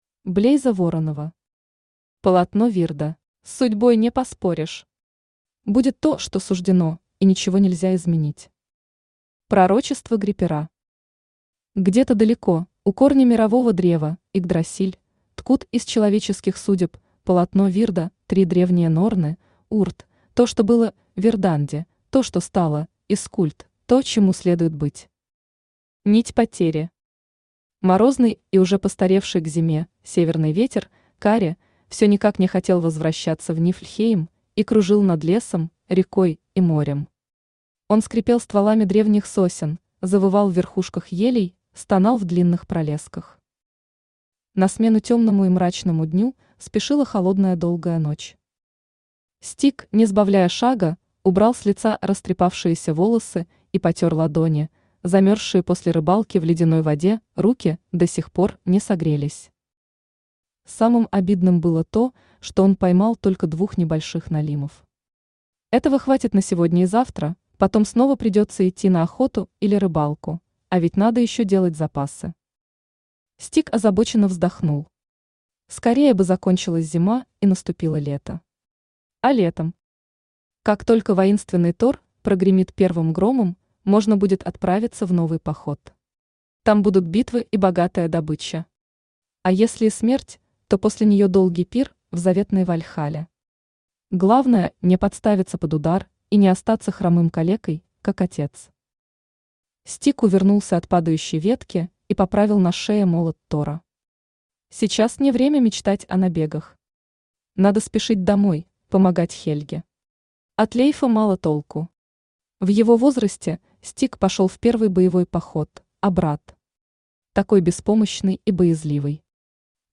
Аудиокнига Полотно Вирда | Библиотека аудиокниг
Aудиокнига Полотно Вирда Автор Блейза Воронова Читает аудиокнигу Авточтец ЛитРес.